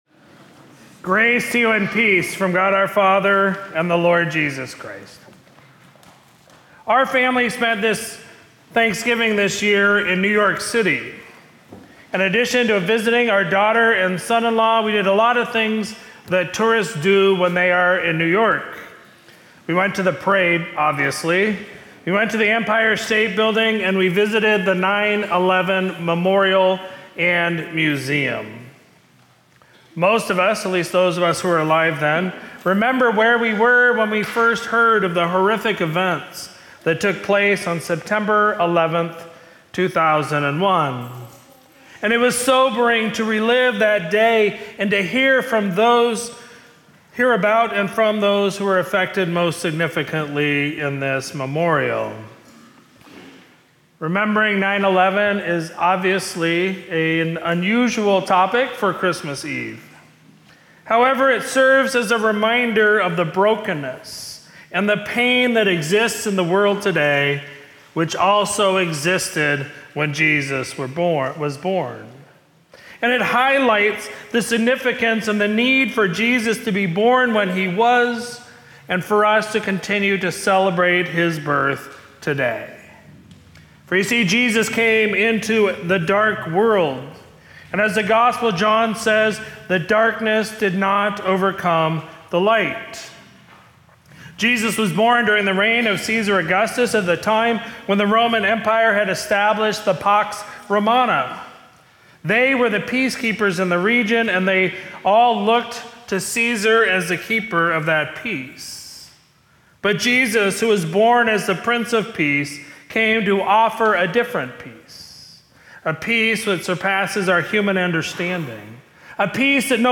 Sermon from Tuesday, December 24, 2024 – Christmas Eve